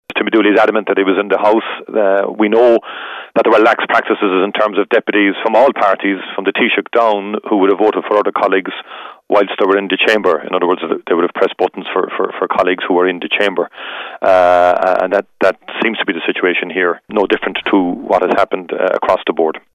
Fianna Fáil leader Michéal Martin has downplayed the latest incident: